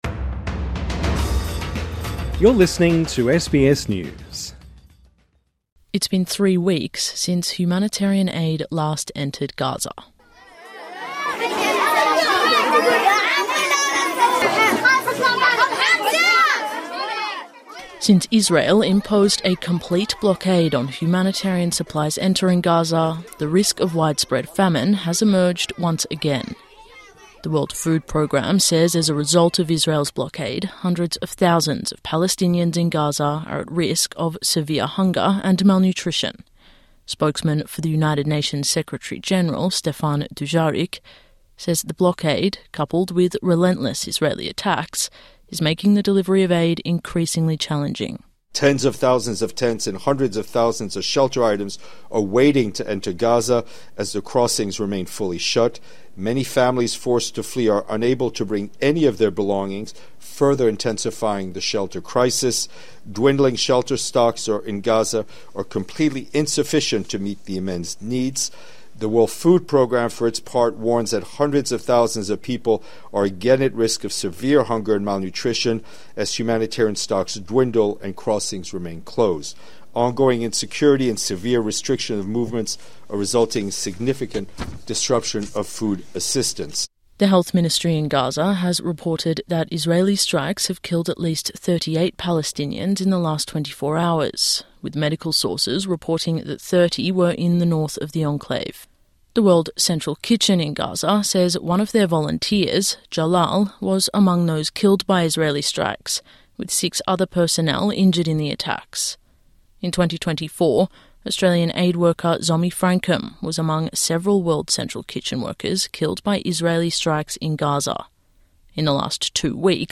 (Sound of pots clanging as people converse in a camp in Gaza) Since Israel imposed a complete blockade on humanitarian supplies entering Gaza, the risk of widespread famine has emerged once again.